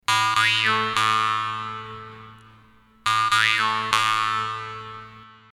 Рингтон и варган
Интересно, что можно сказать про человека, имеющего в качестве рингтона звучание варгана? :)